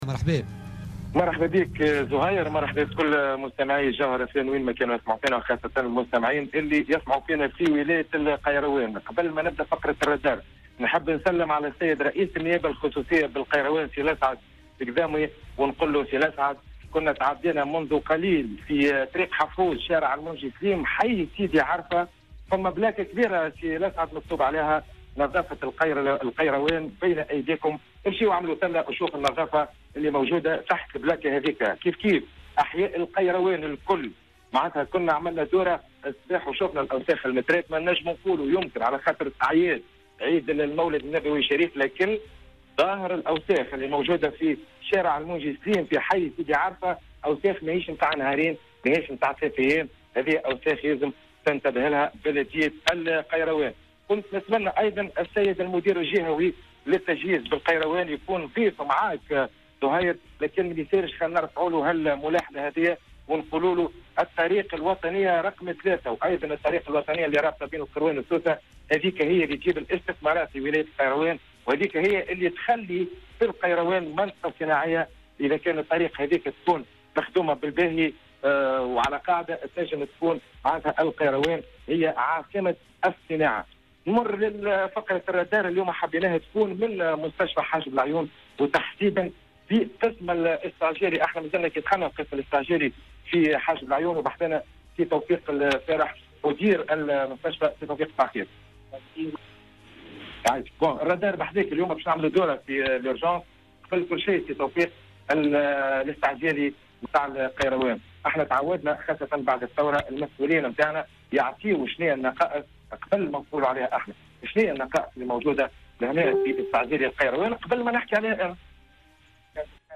زار فريق "الرادار" اليوم الاثنين 12 ديسمبر 2016 قسم الاستعجالي بالمستشفى المحلي بمعتمدية حاجب العيون بالقيروان حيث الإمكانيات الضعيفة والتجهيزات المتواضعة في ظل وجود طبيب واحد وممرضين اثنين و5 سيارات إسعاف فقط.